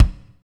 Index of /90_sSampleCDs/Northstar - Drumscapes Roland/DRM_Medium Rock/KIK_M_R Kicks x